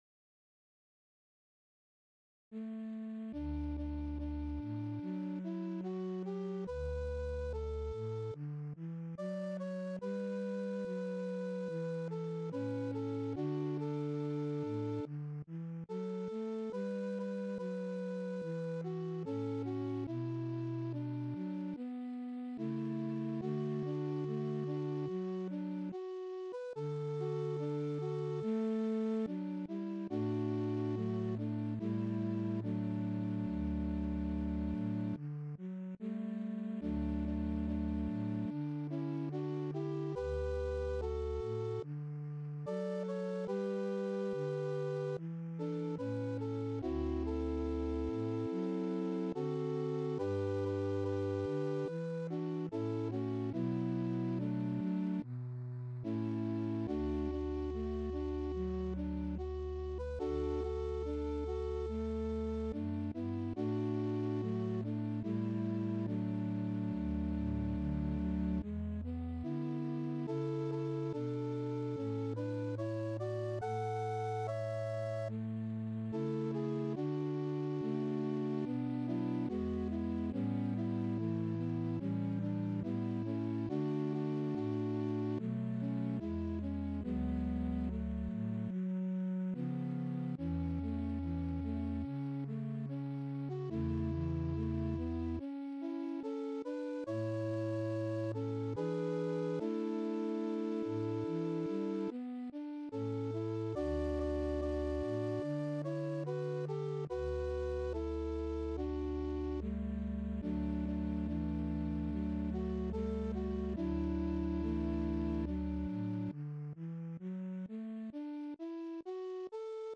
DIGITAL SHEET MUSIC - CLASSIC GUITAR SOLO
Classic Guitar, Traditional Ballad ,Folk